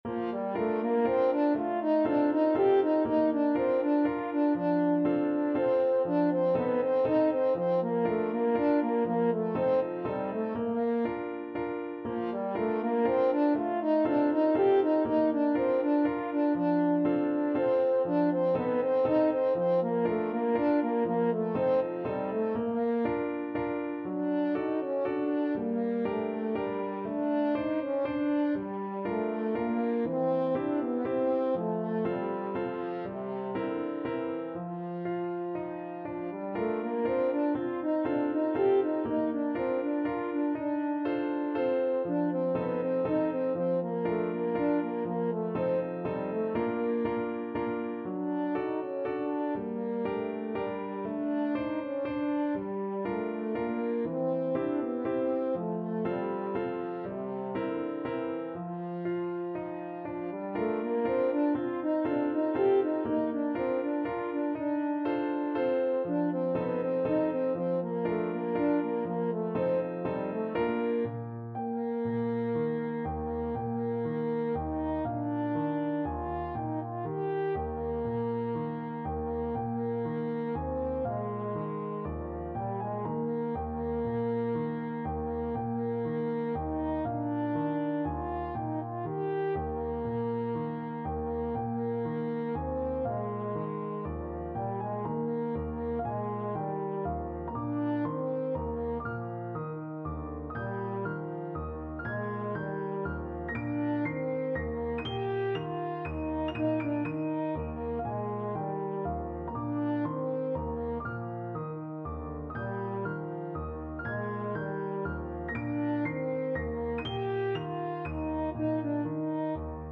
Free Sheet music for French Horn
French Horn
3/4 (View more 3/4 Music)
Bb major (Sounding Pitch) F major (French Horn in F) (View more Bb major Music for French Horn )
Classical (View more Classical French Horn Music)